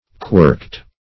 (kw[~e]rkt)